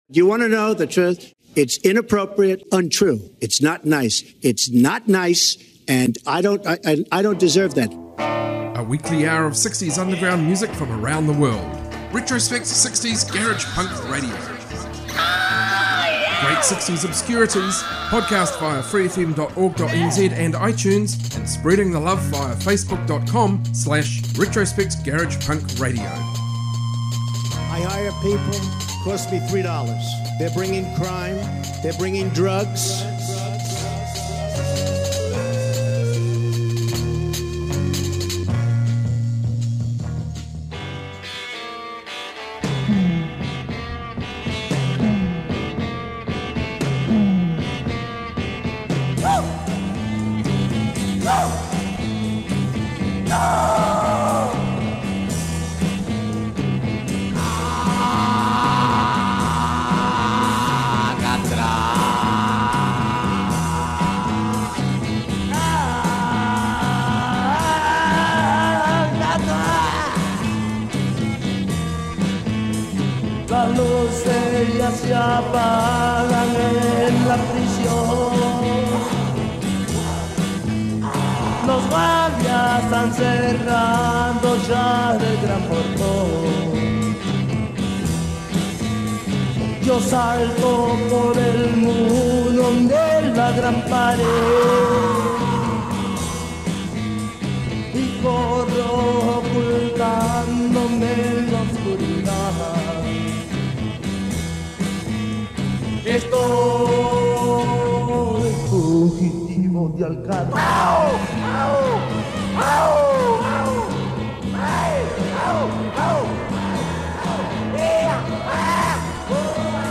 Garage rock, garage punk, proto punk, freakbeat